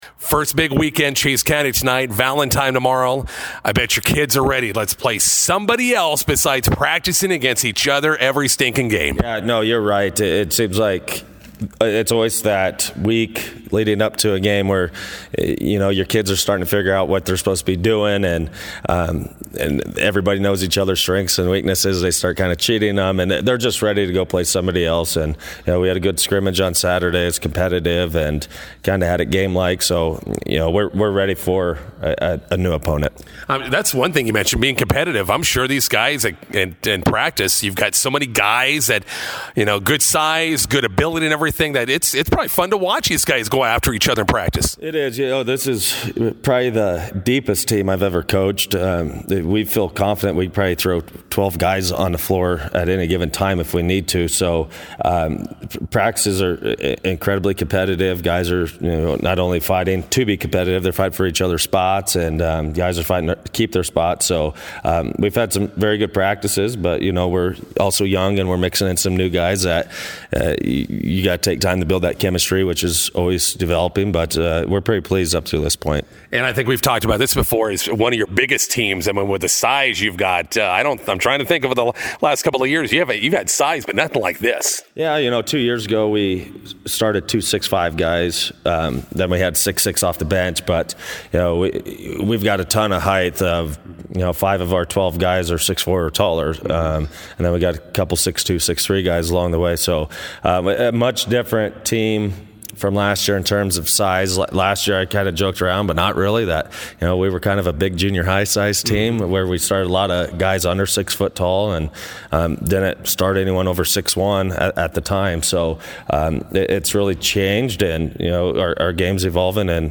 INTERVIEW: Bison basketball teams open 2023-24 tonight at Chase County.